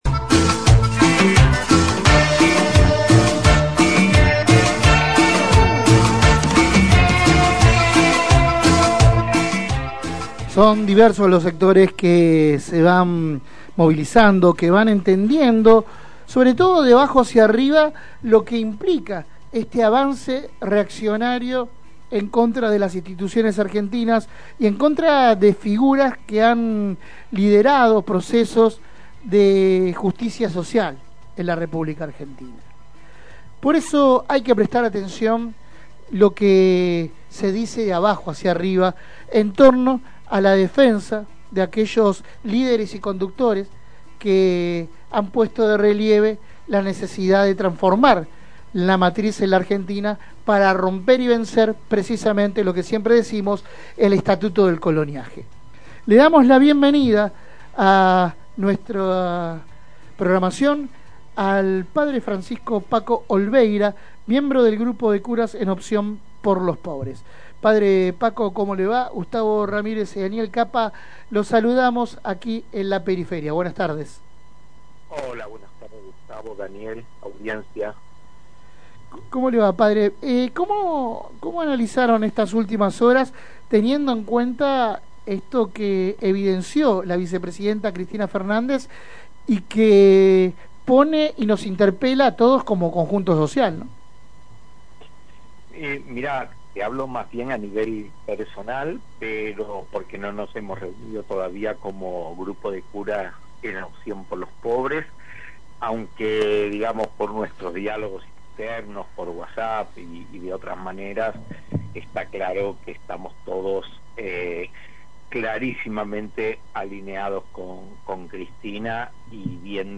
Compartimos la entrevista conpleta: